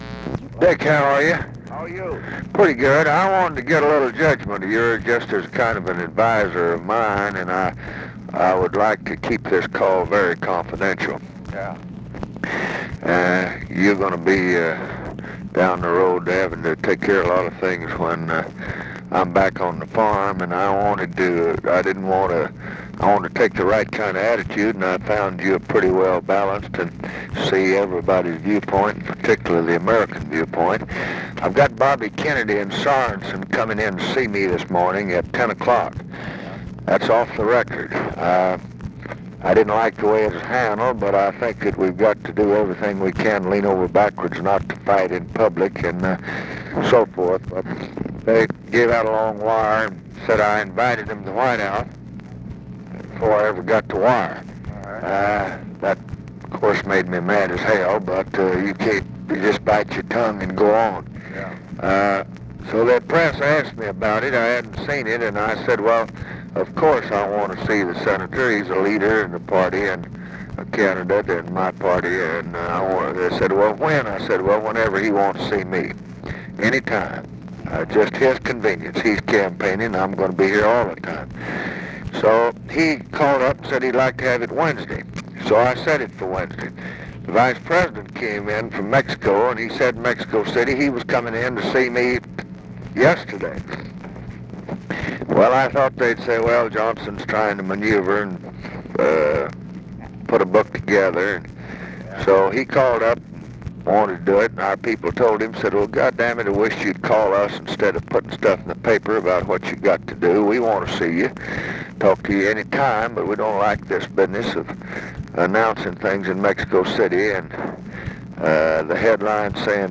Conversation with RICHARD DALEY, April 3, 1968
Secret White House Tapes